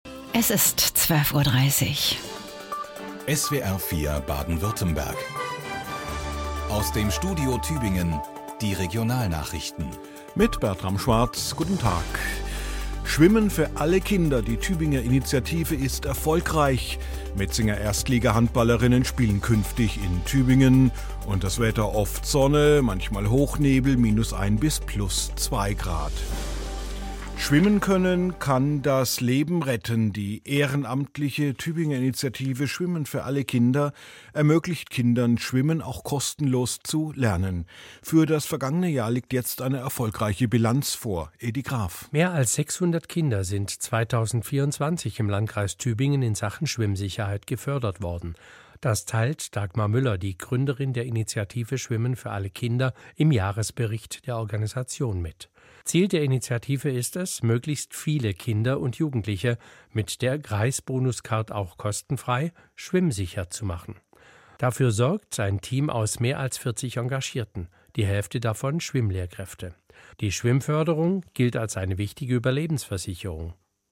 2025-02-18_SfaK_-_SWR4_Regionalnachrichten_12-30.mp3 (Dateigröße: 1,13 MB, MIME-Typ: audio/mpeg)